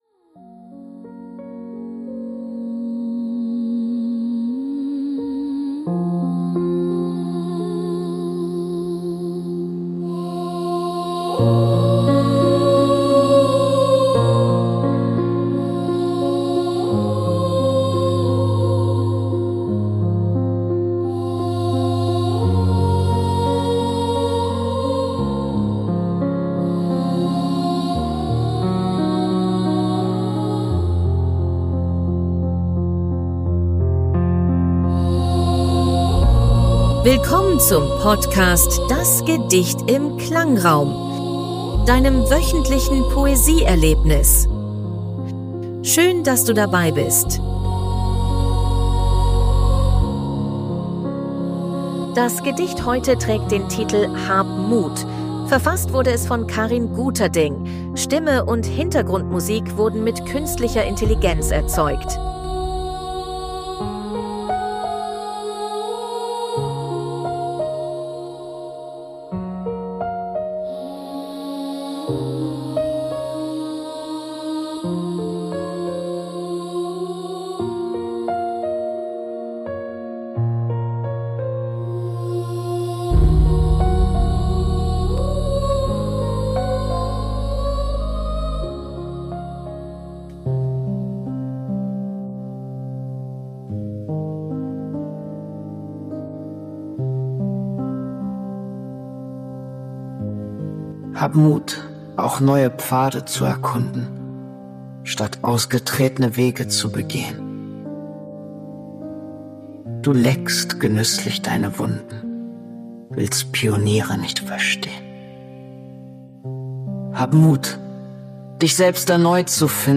Hintergrundmusik wurden mit KI erzeugt. 2025 GoHi (Podcast) -